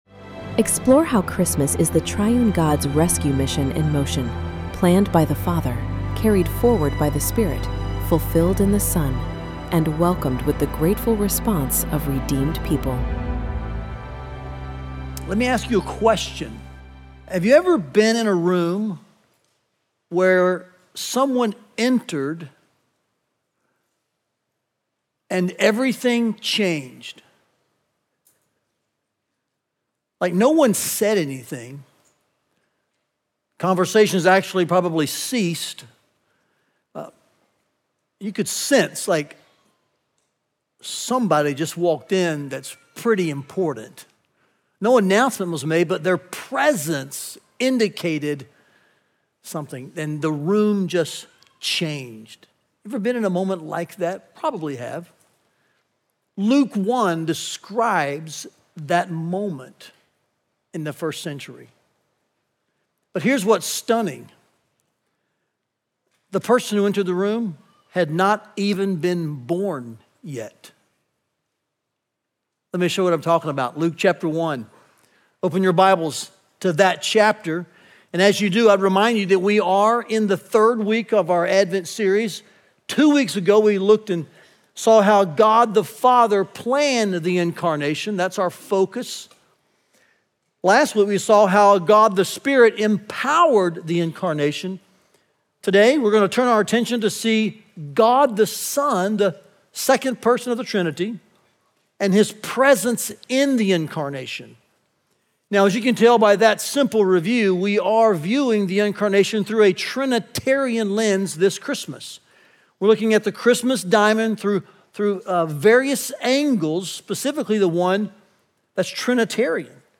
Listen to the third sermon from Advent 2025 and learn more about our Advent series here.